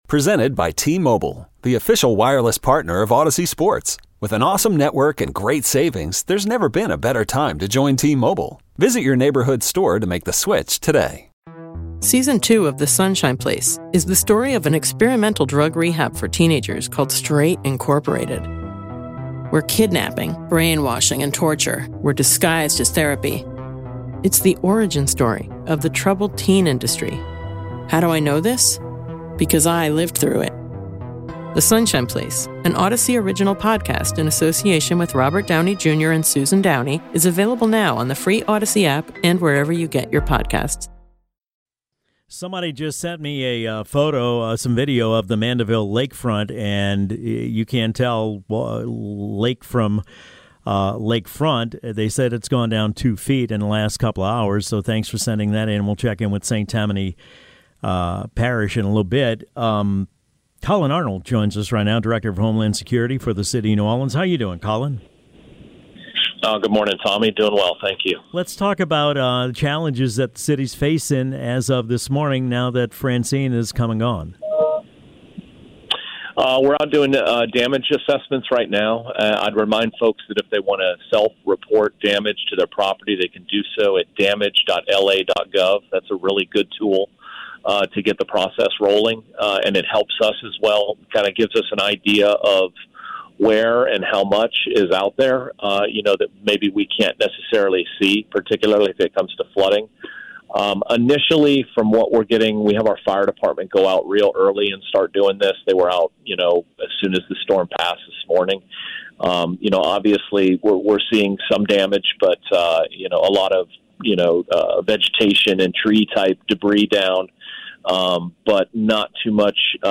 Collin Arnold, Director of Homeland Security & Emergency Preparedness for New Orleans